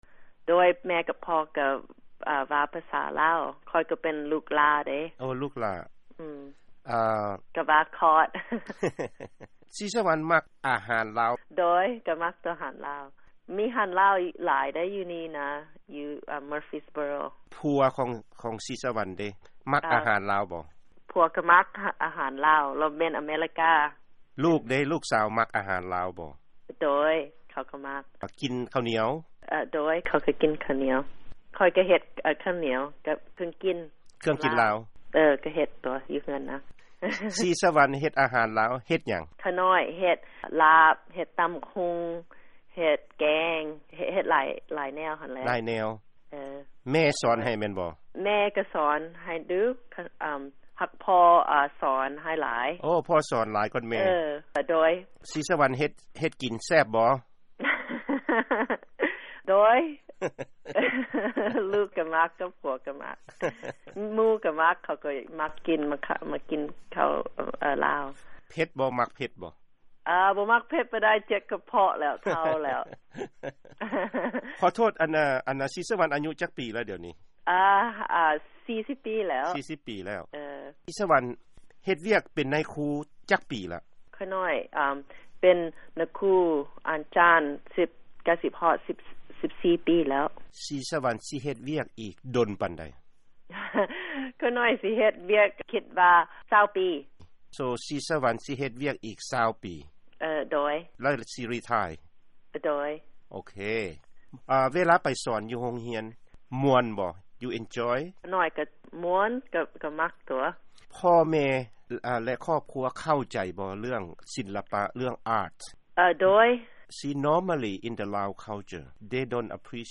ສຳພາດ